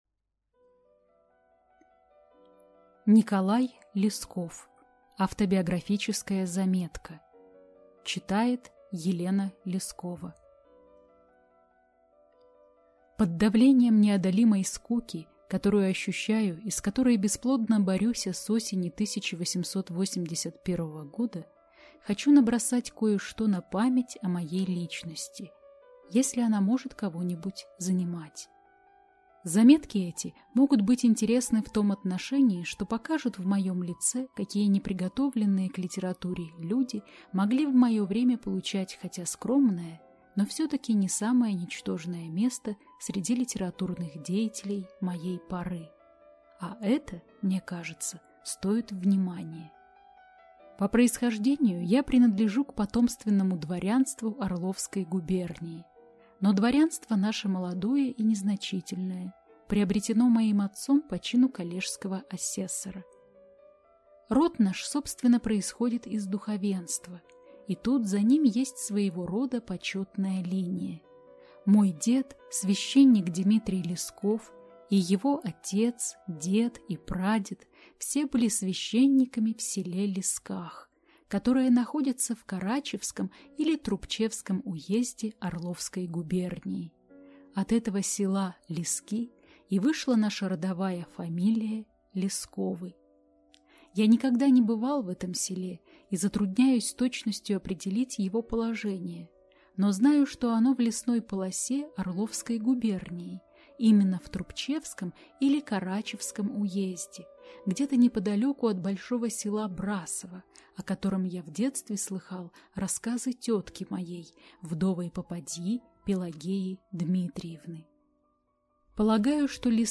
Аудиокнига Автобиографическая заметка | Библиотека аудиокниг